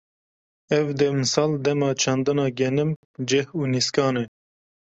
Pronounced as (IPA) /ɡɛˈnɪm/